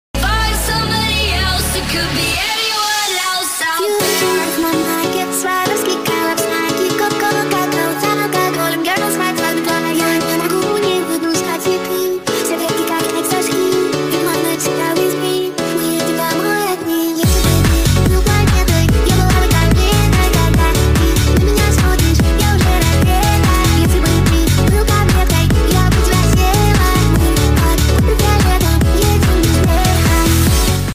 • Качество: 128 kbps, Stereo